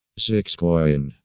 Pronounced - (ziks.koin) "ziksCoin"